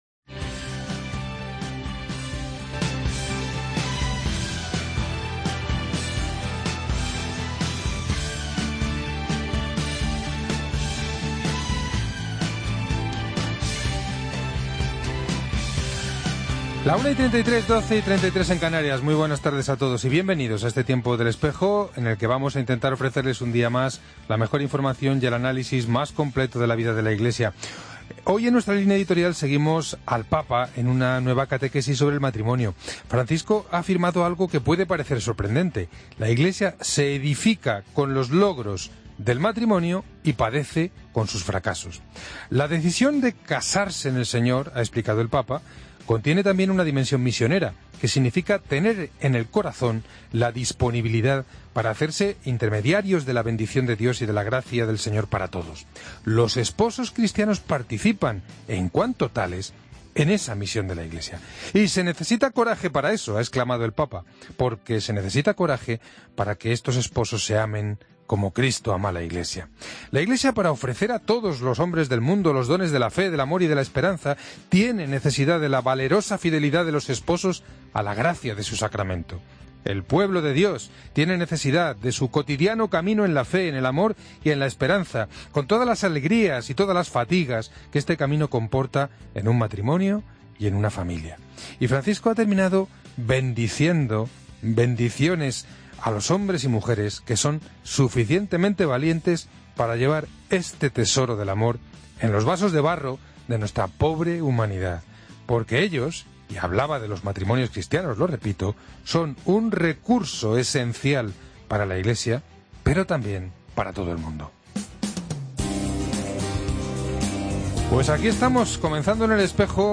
Hoy contemplamos la figura del gran misionero franciscano mallorquín, Fray Junípero Serra, que será canonizado por el Papa Francisco en Washington, el próximo 23 de septiembre. Para ello, está con nosotros el historiador